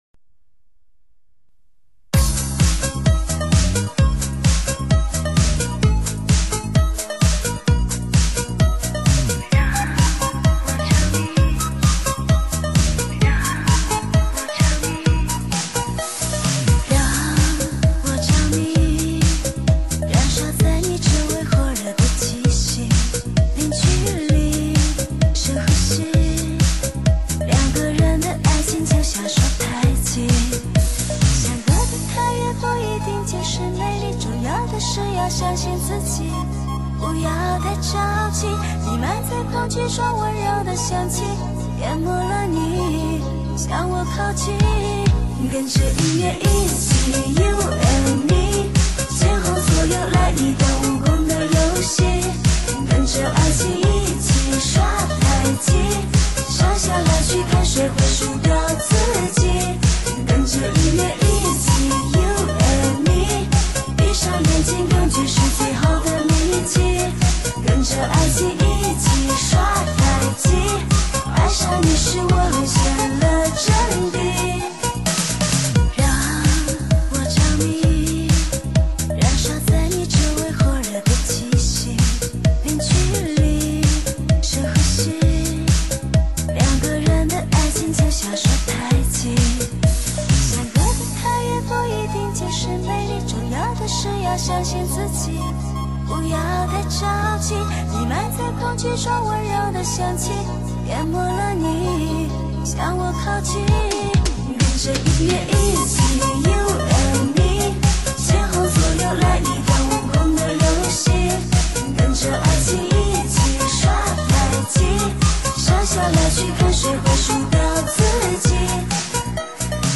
中文最新慢摇歌曲精选 汽车音响